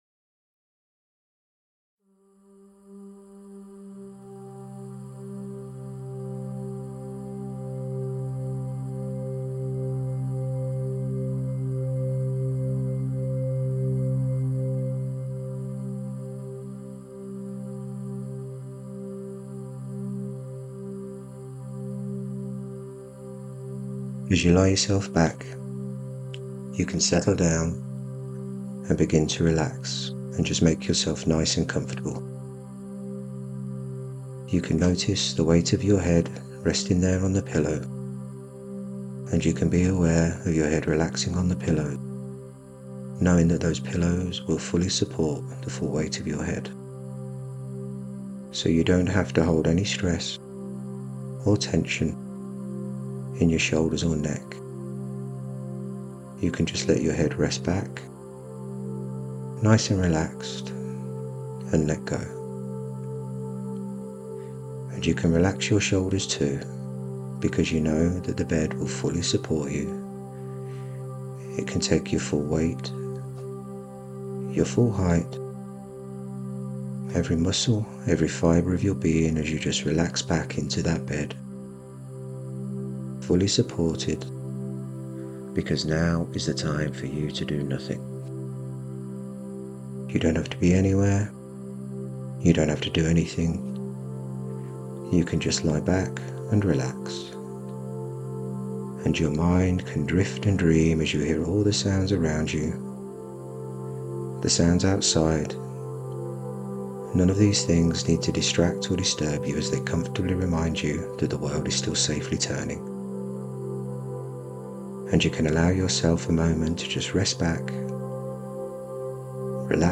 Free relaxation audio
Free relaxation audio to help you sleep better, audio to feel relaxed, free guided relaxation, free guided meditation,